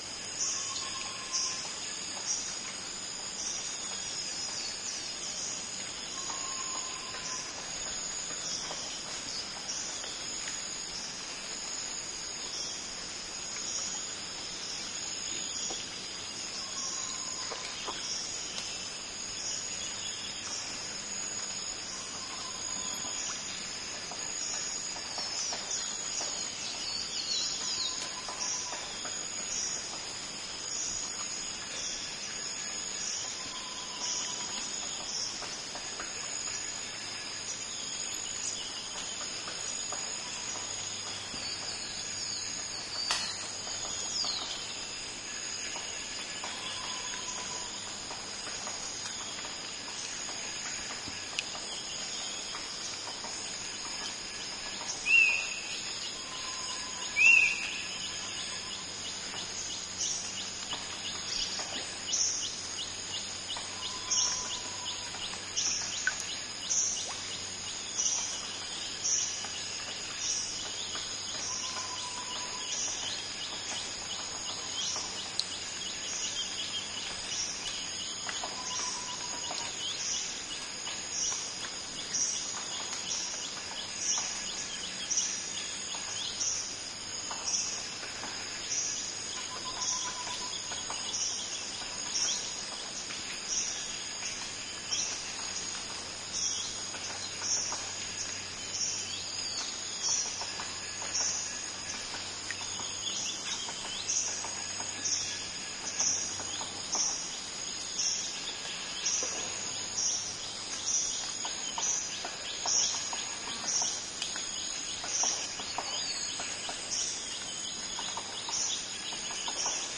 描述：从上方现场记录自来水
Tag: 现场记录